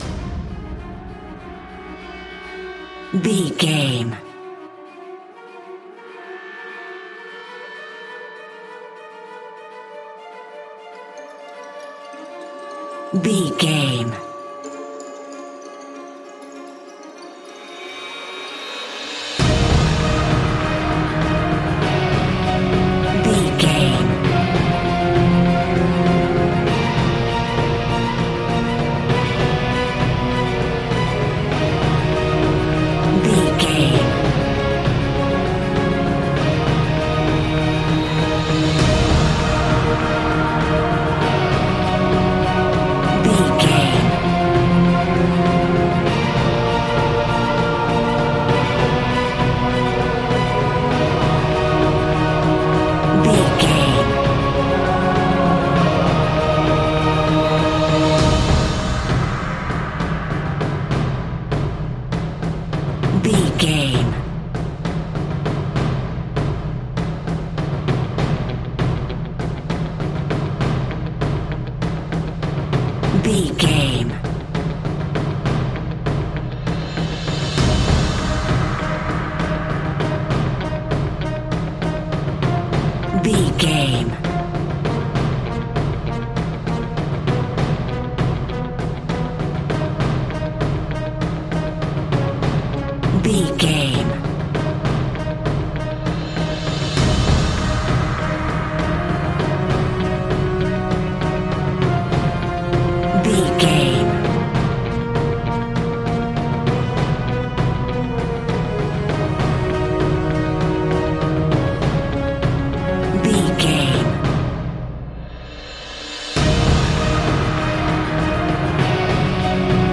Aeolian/Minor
E♭
ominous
dramatic
driving
powerful
strings
brass
percussion
cello
cinematic
orchestral
taiko drums
timpani